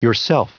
Prononciation du mot yourself en anglais (fichier audio)
Prononciation du mot : yourself